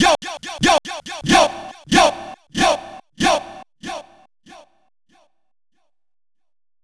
Exodus - Yo Vocal.wav